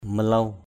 /mə-lau/